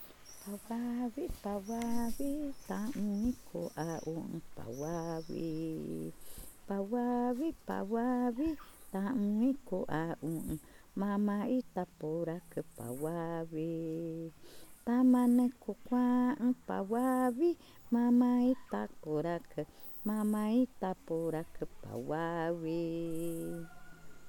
Cushillococha
Arrullo wawae